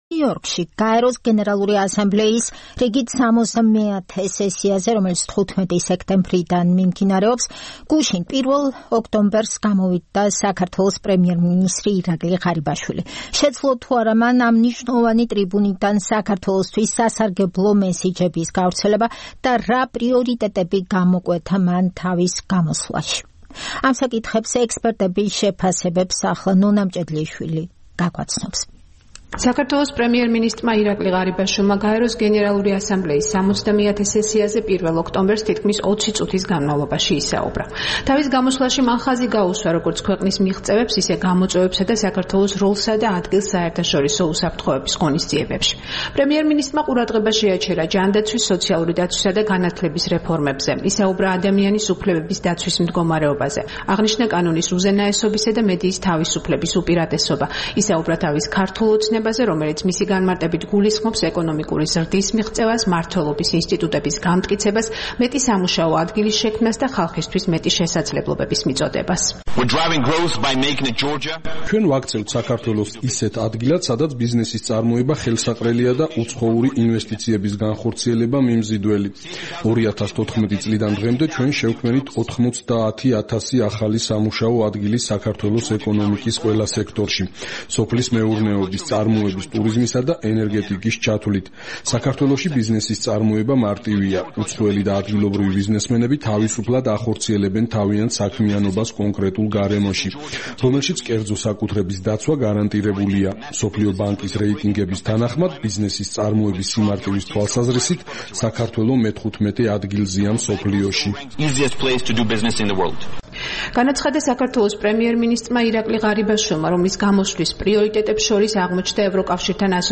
ირაკლი ღარიბაშვილის გამოსვლა გაეროში